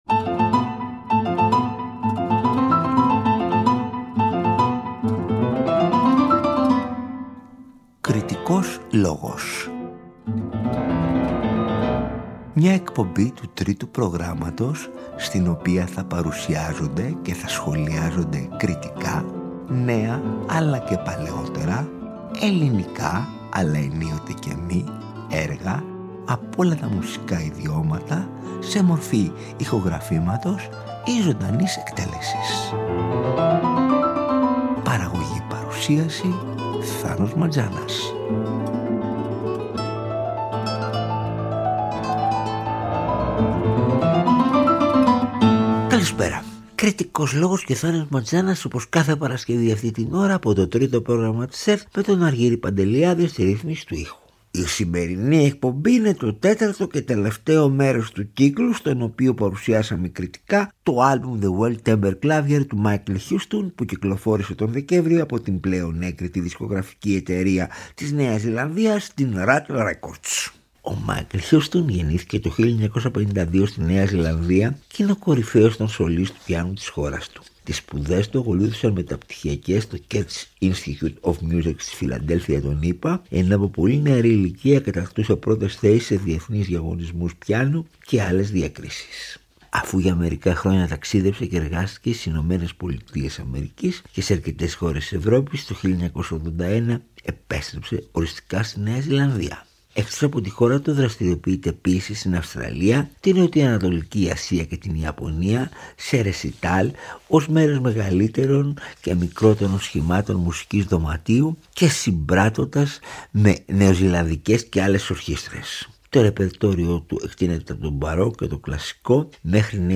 Ακούστε την τέταρτη -και τελευταία- που μεταδόθηκε την Παρασκευή 31 Ιανουαρίου 2025 από το Τρίτο Πρόγραμμα.